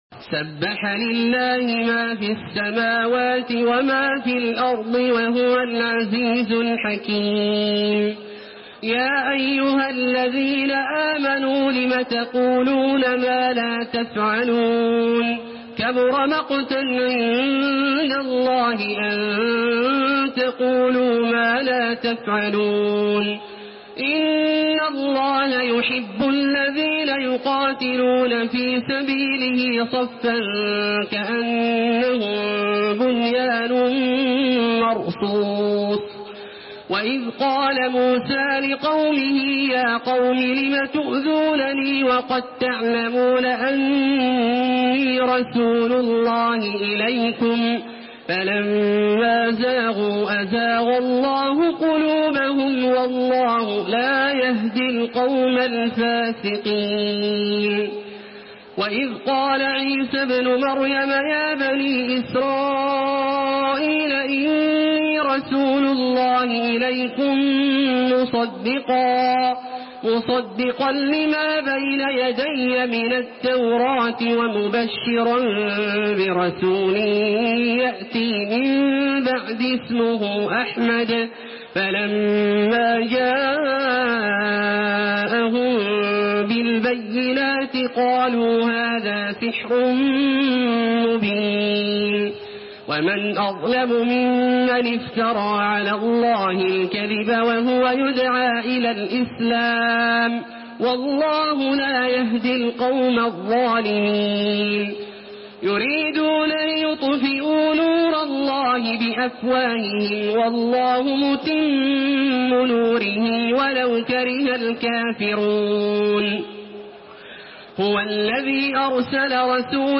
تحميل سورة الصف بصوت تراويح الحرم المكي 1432
مرتل حفص عن عاصم